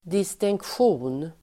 Ladda ner uttalet
Uttal: [dis:tingksj'o:n]
distinktion.mp3